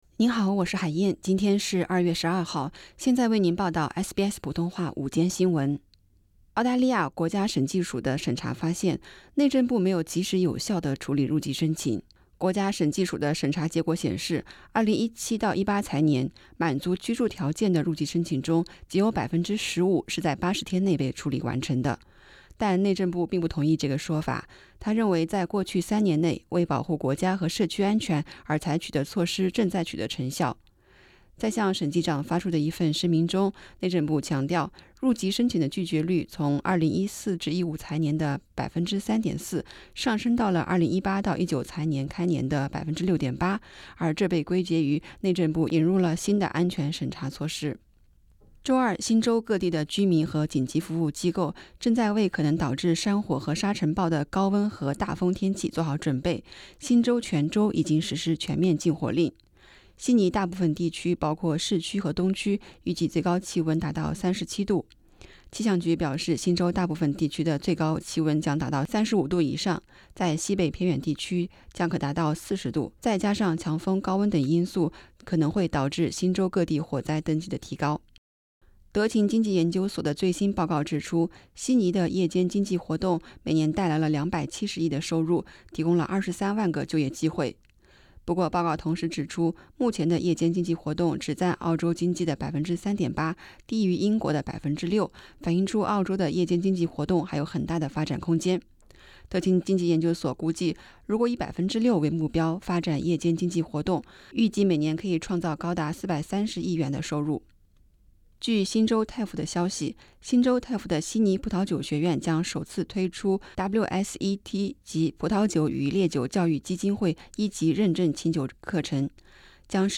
SBS 午间新闻 （2月12日）